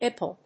アイ‐ピーエル